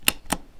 button1.ogg